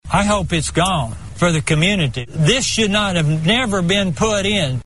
This man has long worried about the plant.